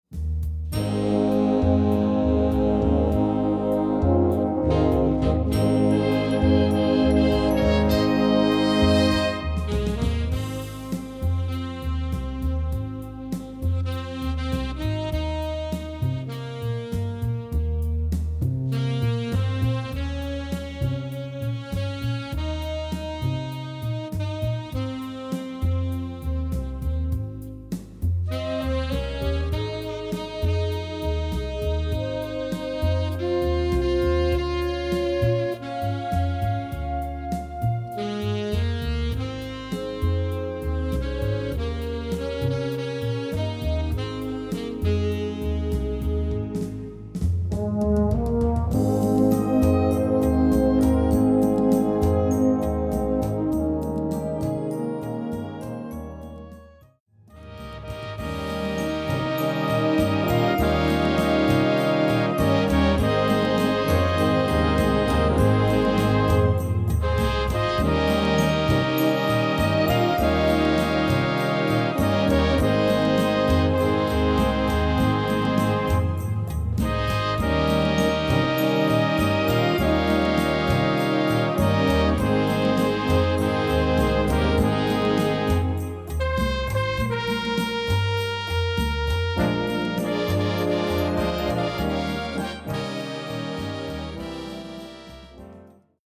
Gattung: Kirche
Besetzung: Blasorchester